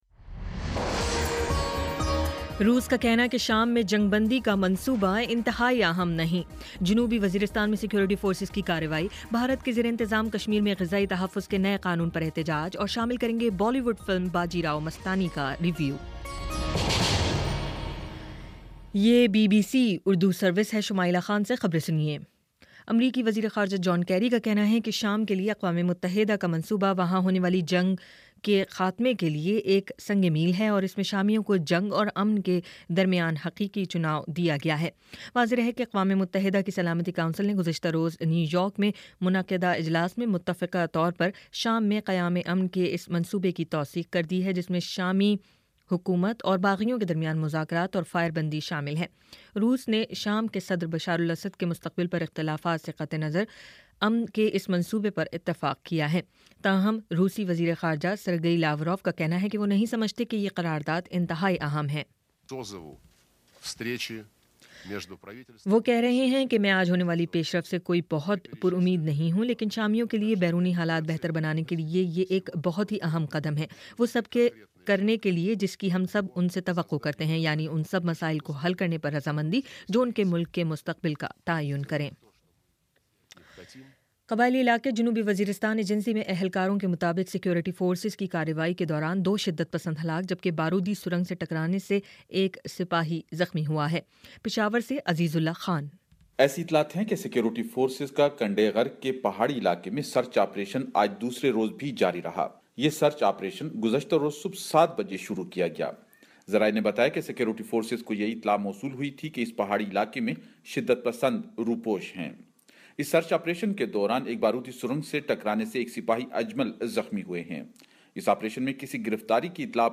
دسمبر 19 : شام چھ بجے کا نیوز بُلیٹن